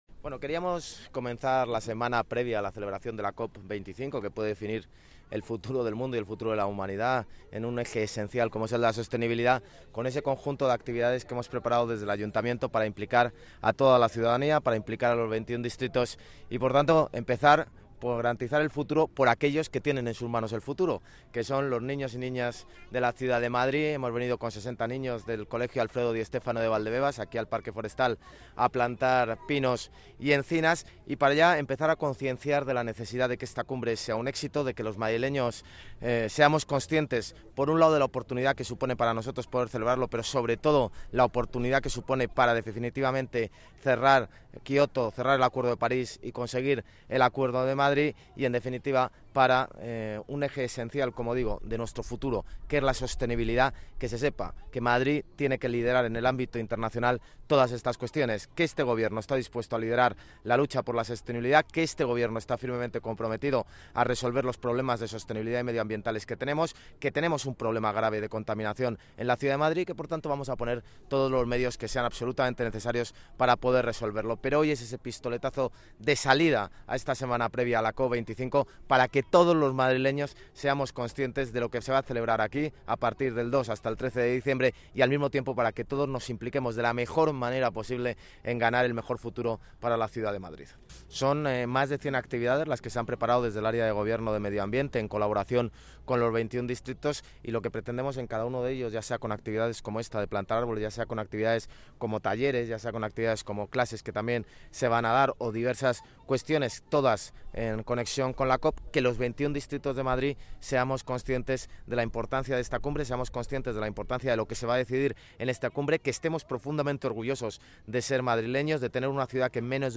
Almeida participa en una plantación de árboles con 60 escolares con motivo de Madrid Green Capital